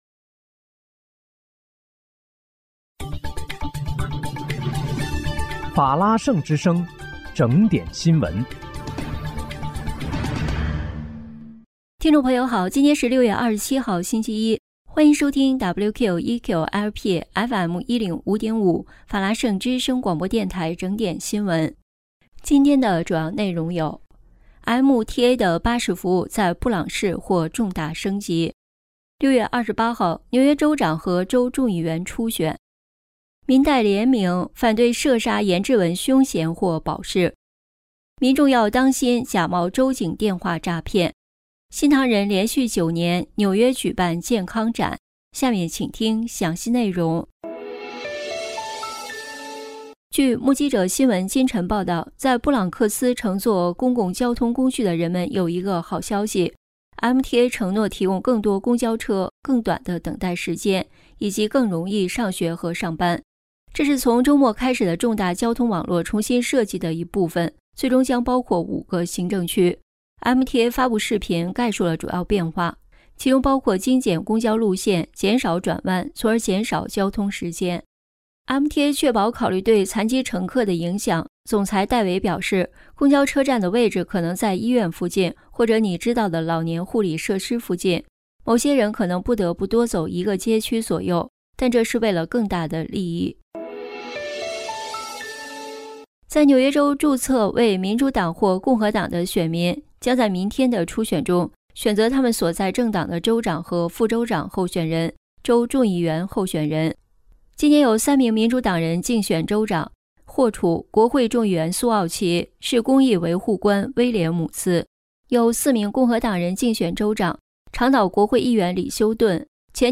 6月27日（星期一）纽约整点新闻